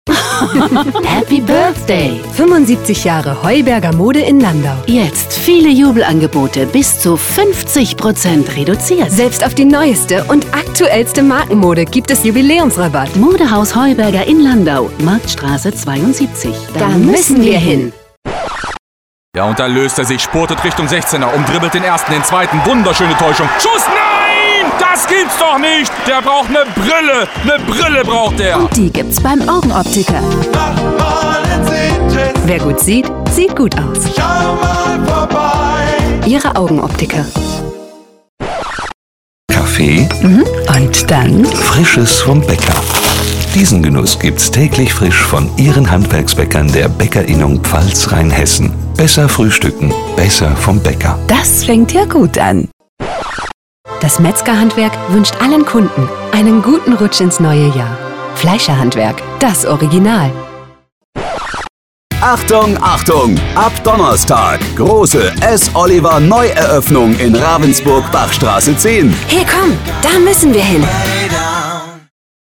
Sprecherin (Dipl.